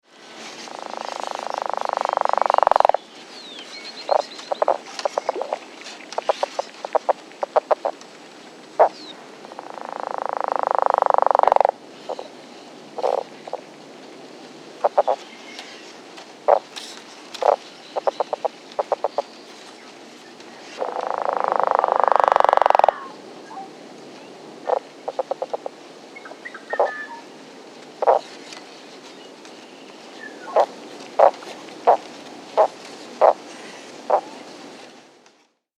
The advertisement call of the Northern Leopard Frog is a moderately loud low gutteual snore-like rattle, which has been compared to a small motor boat engine.
The five sound files below were all recorded at the same pond in Grant County, Washington, shown in the three pictures below.
Sound This is a 35 second edited recording of a Northern Leopard Frog calling during daylight in mid April. Dry reeds and bird sounds can be heard in the background.